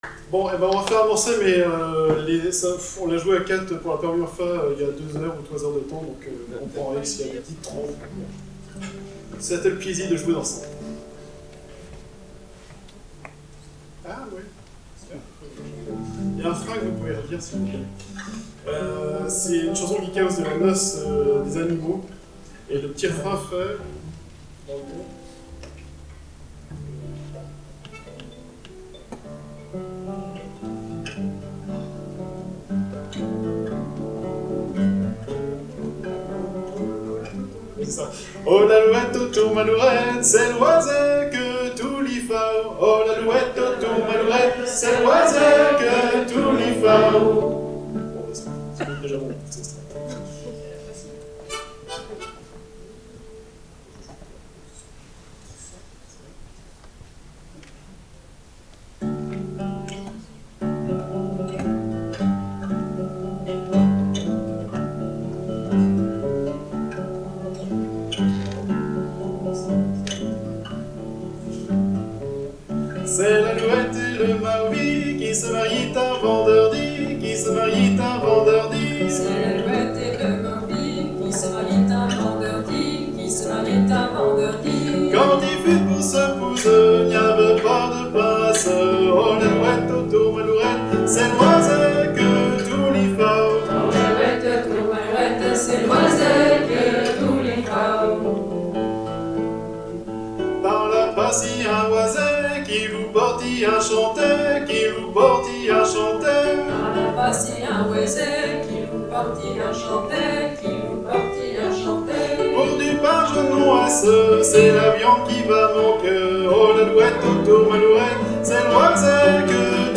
:mp3:2019:07_festival:concert_mardi
quatuor_final_argueliss_abrasive-l_allouette.mp3